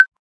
menuhit.ogg